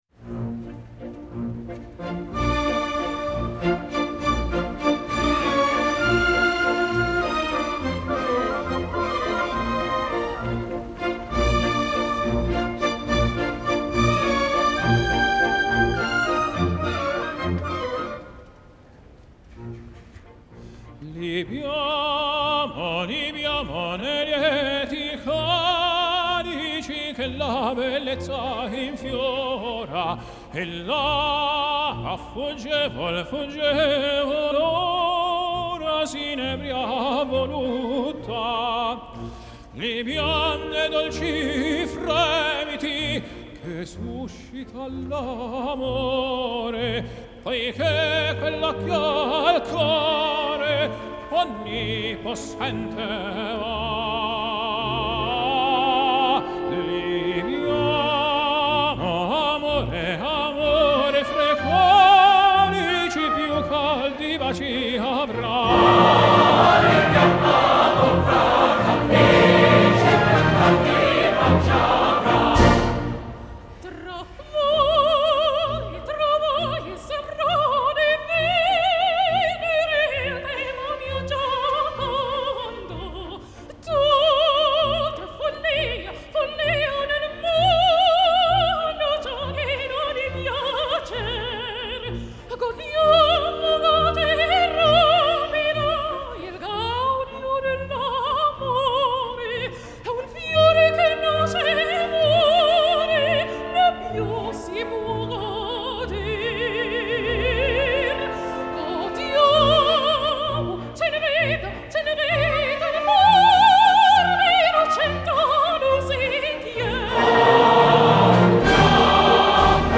Genre : Classical
Live At Central Park, New York